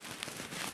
crumple5.ogg